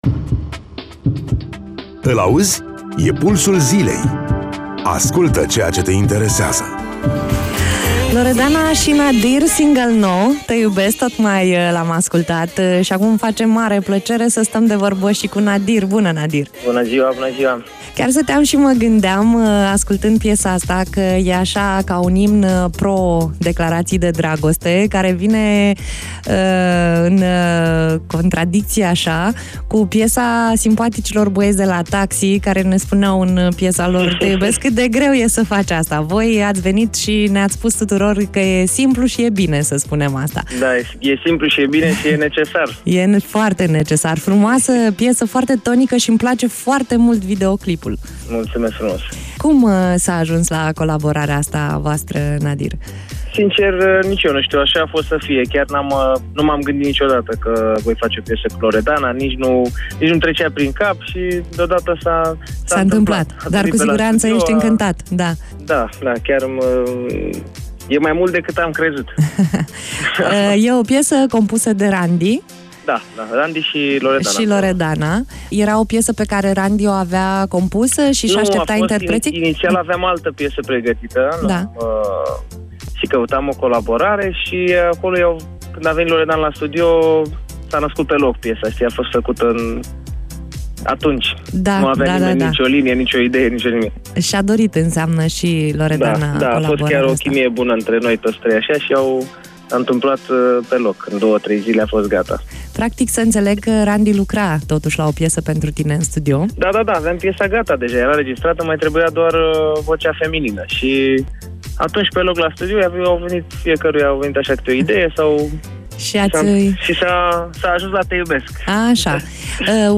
Interviu-pt-site-Nadir.mp3